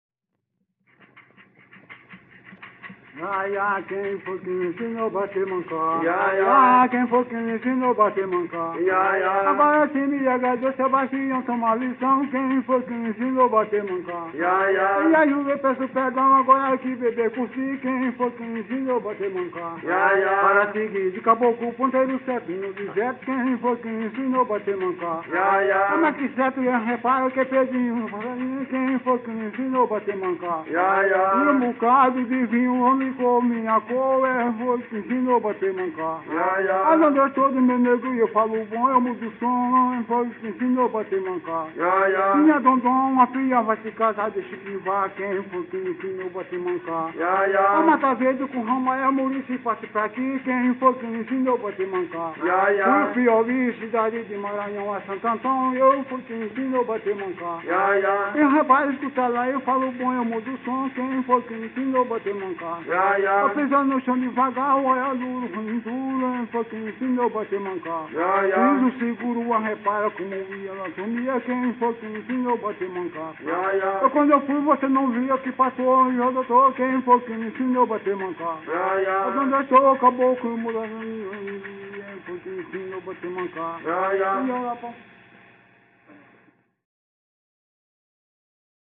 Coco- “”Oh laia”” - Acervos - Centro Cultural São Paulo